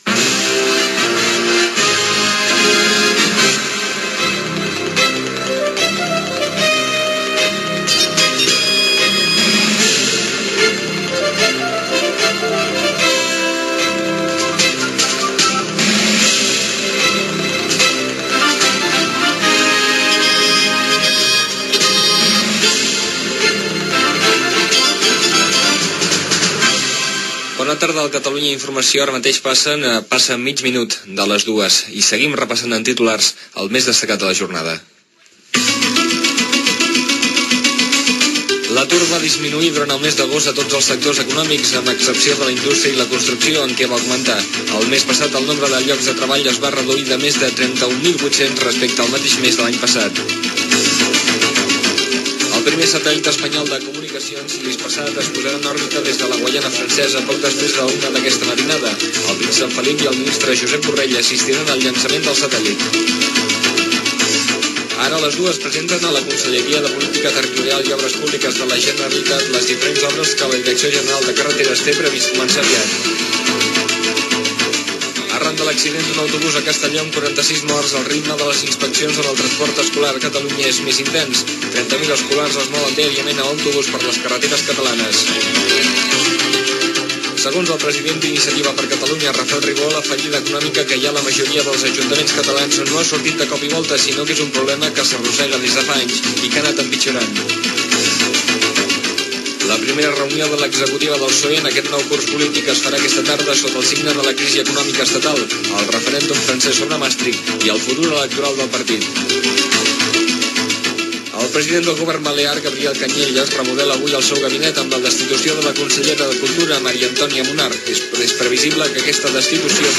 Emissió en proves el dia abans de la seva inauguració.
Sintonia, identificació, hora i resum informtiu: disminució de l'atur, Hispasat, obres a les carreteres, transport escolar, etc. Identificació i hora.
Informatiu
FM